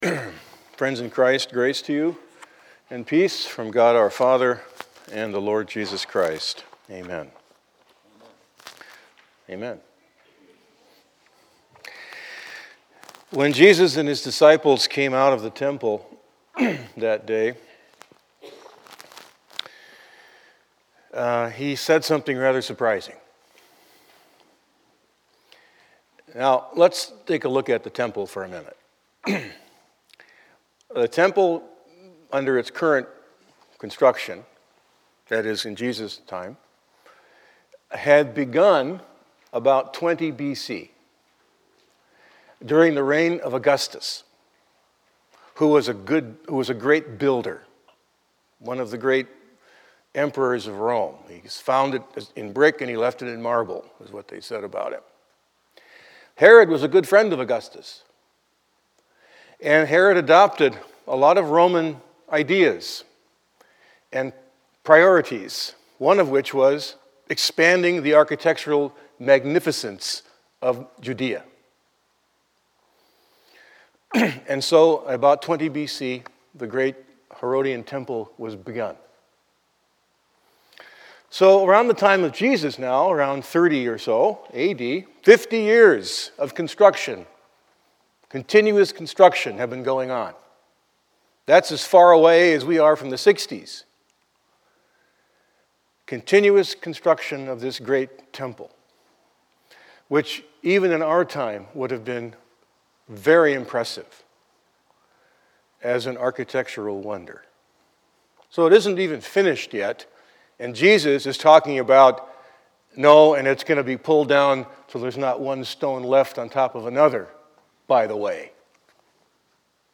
Pastor's class